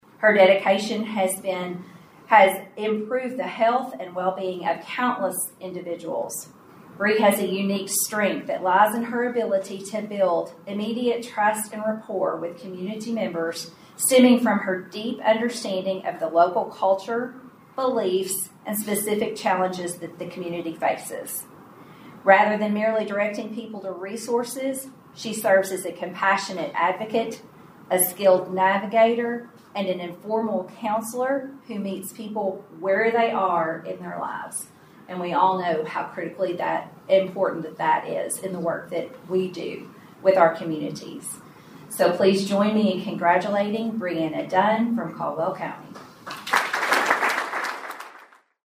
The United Way of the Coalfield Award Celebration took place at the Steve Beshear Center for Post-Secondary Education on the Madisonville Community College campus.